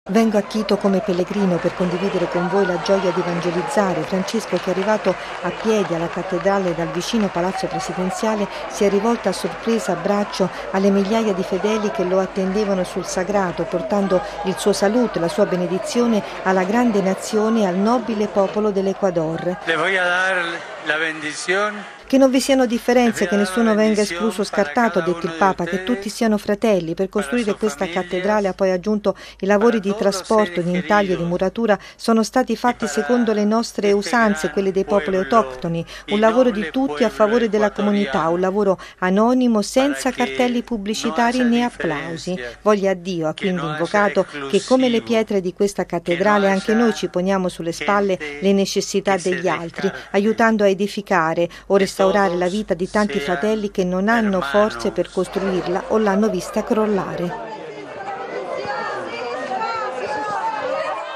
“Che non vi siano differenze, che nessuno venga escluso”: così il Papa all’immensa folla raccolta ad aspettarlo ieri sera fuori dalla cattedrale di Quito, dove si è raccolto in preghiera, dopo l’incontro con il Presidente ecuadoriano.